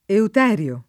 vai all'elenco alfabetico delle voci ingrandisci il carattere 100% rimpicciolisci il carattere stampa invia tramite posta elettronica codividi su Facebook euterio [ eut $ r L o ] s. m. (zool.); pl. -ri (raro, alla lat., -rii )